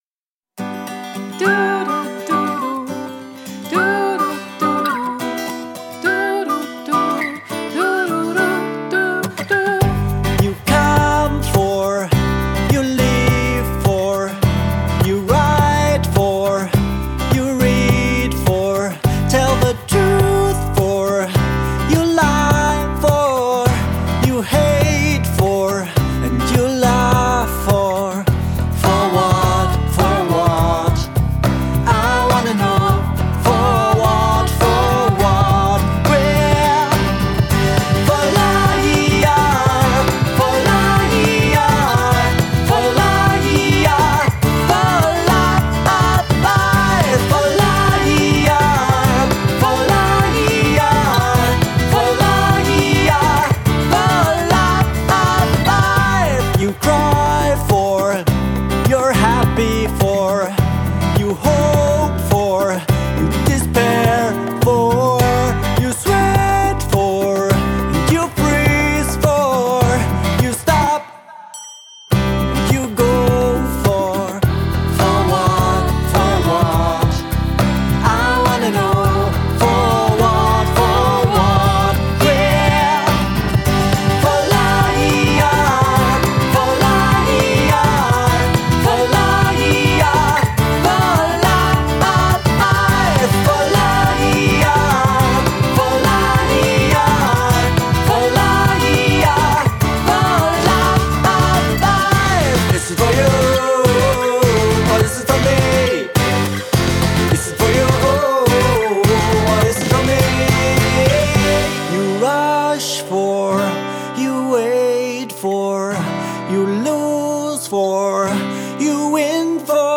Die Songs basieren auf Gitarre und Gesang.